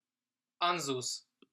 How to pronounce Ansuz